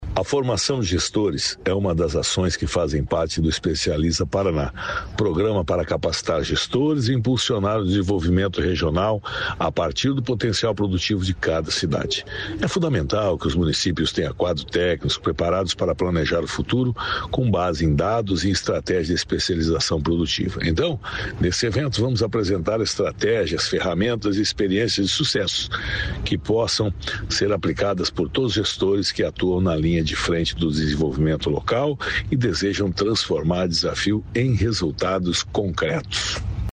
Sonora do secretário da Indústria, Comércio e Serviços, Marco Brasil, sobre capacitação de gestores